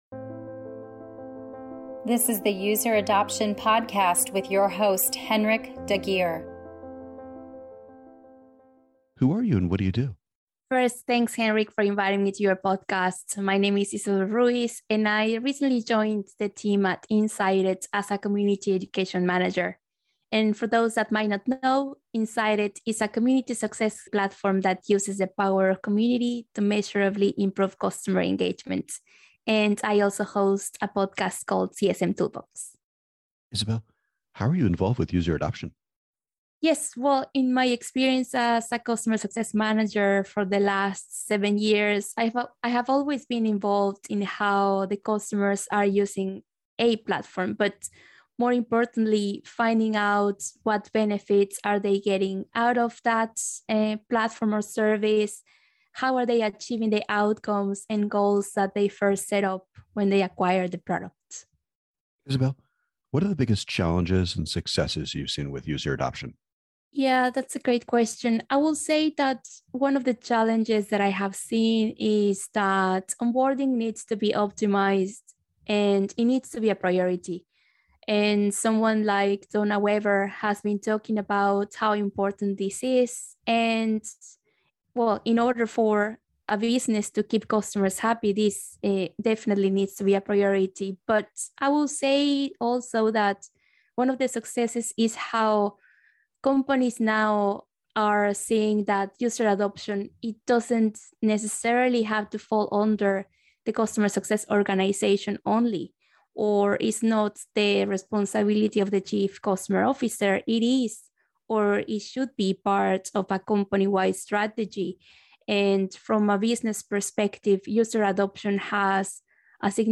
Here is a User Adoption Podcast interview